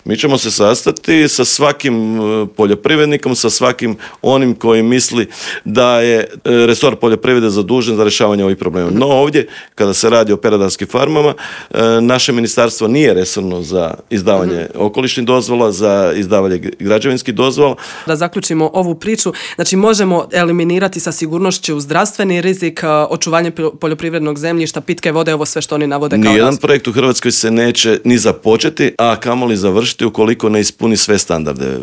ZAGREB - U novom izdanju Intervjua Media servisa gostovao je predsjednika HDZ-a Novi Zagreb Istok i državnog tajnika u Ministarstvu poljoprivrede Tugomir Majdak s kojim smo prošli teme od gorućih problema u Novom Zagrebu, preko najavljenog prosvjeda u Sisku protiv industrijskih megafarmi i klaonice pilića pa sve do ovisnosti Hrvatske o uvozu hrane.